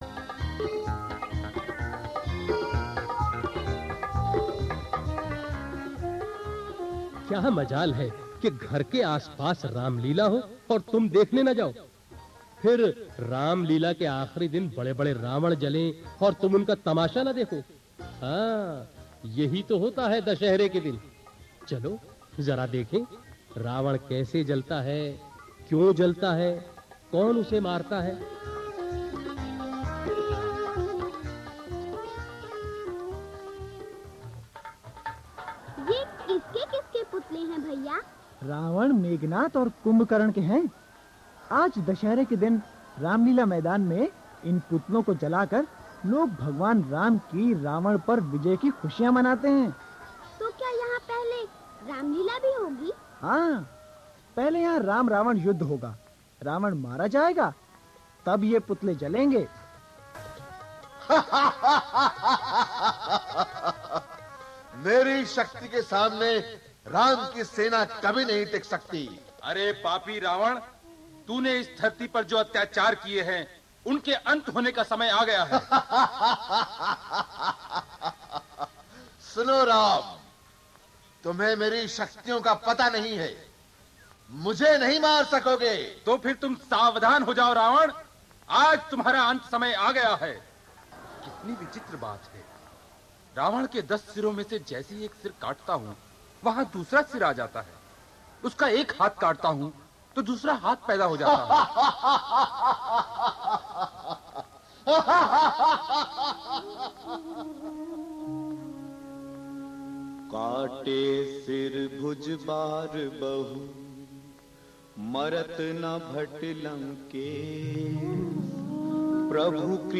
A Skit on How and Why Dushehra is Celebrated
आईये आज सुनते हैं दशहरे पर एक रूपक जो बच्चों को अच्छा लगेगा और आपको भी!